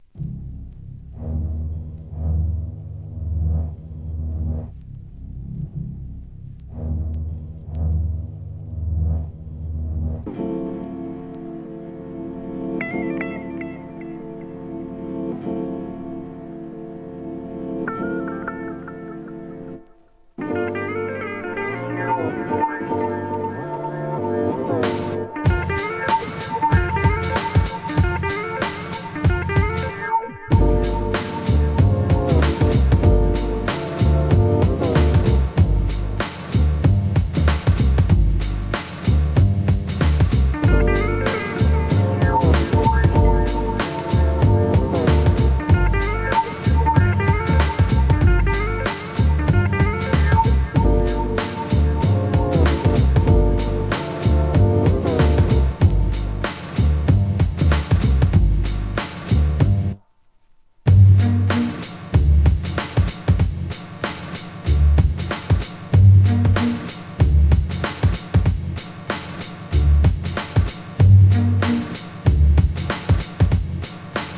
HIP HOP/R&B
アブストラクト・ブレイク・ビーツ